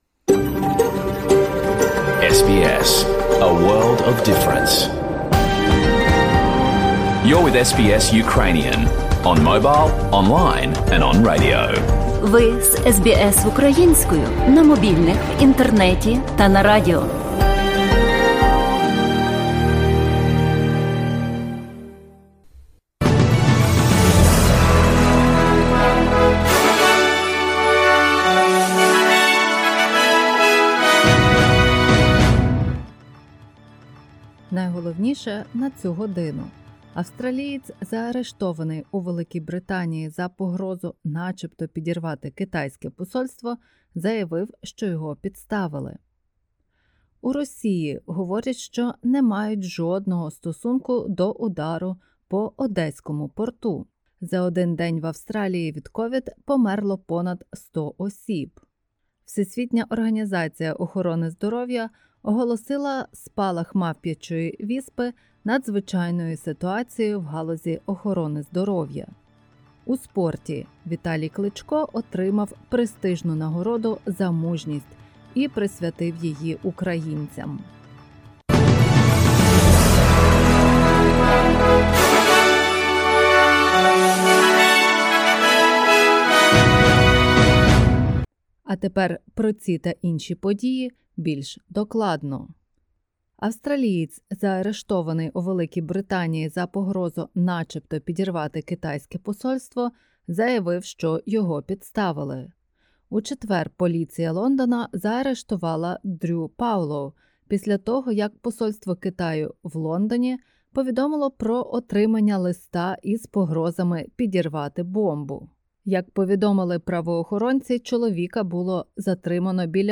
SBS News in Ukrainian - 24/07/2022